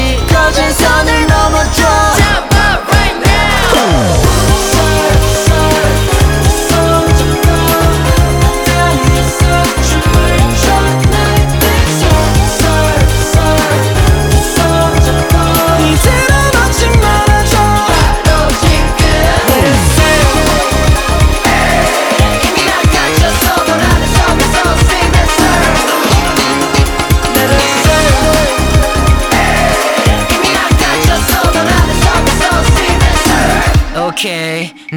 K-Pop
2025-08-12 Жанр: Поп музыка Длительность